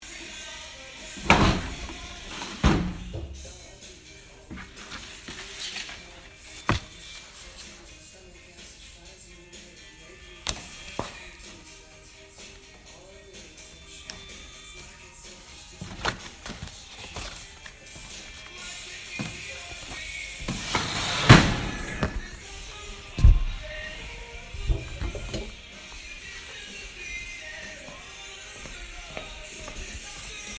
Field Recording